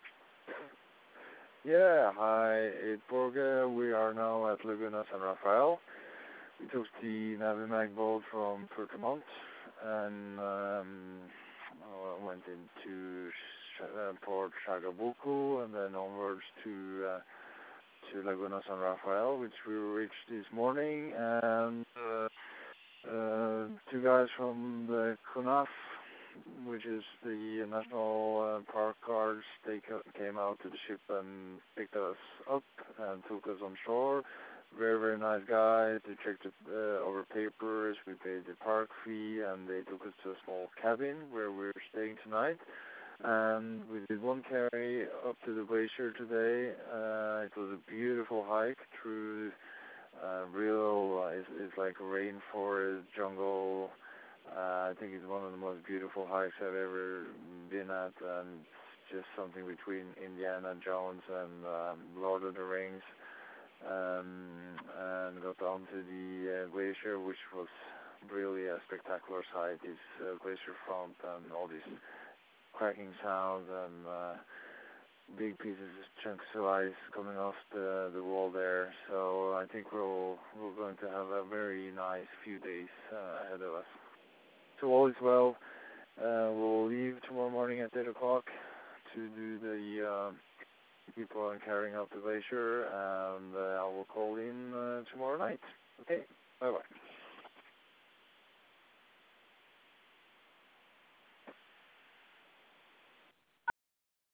At Laguna San Rafel